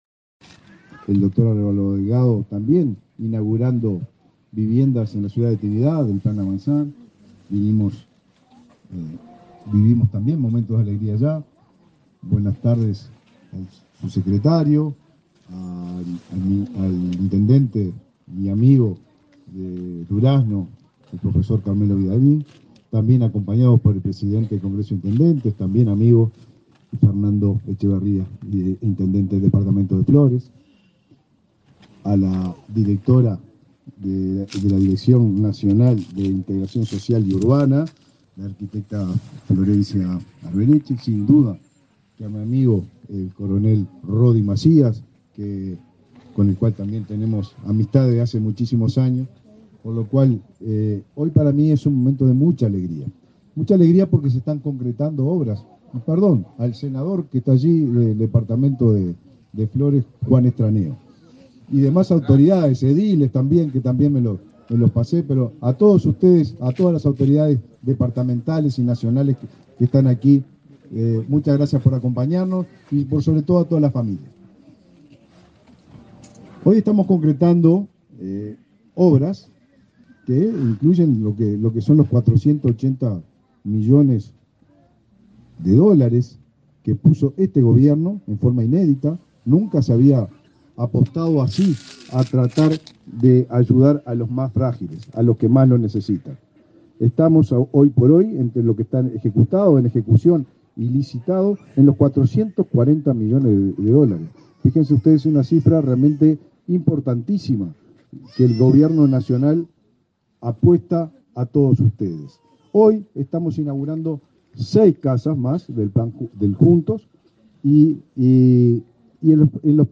Acto de la entrega de viviendas en la ciudad de Durazno
En la oportunidad, se expresaron el citado jerarca y el titular de la cartera, Raúl Lozano.